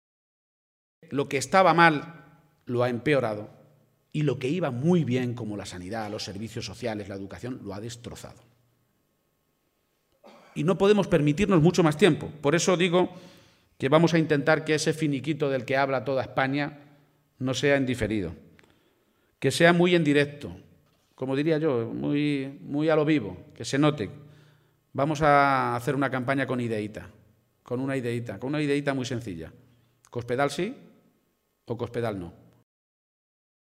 El secretario general regional y candidato del PSOE a la Presidencia de Castilla-La Mancha, Emiliano García-Page, ha aprovechado hoy un acto público en la localidad toledana de Sonseca para hacer balance de estos cuatro años de legislatura de Cospedal y ha sido muy rotundo al señalar que «hay más paro, más deuda, más soberbia, más mentira y menos sanidad, menos educación y menos población.
Audio García-Page en Sonseca 3